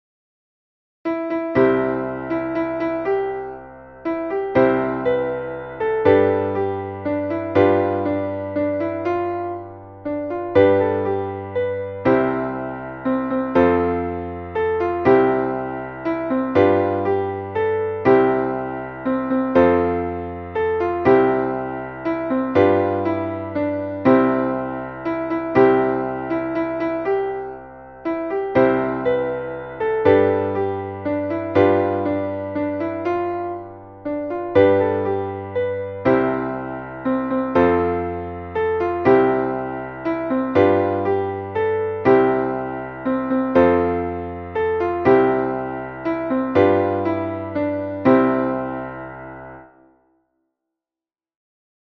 Traditionelles Volkslied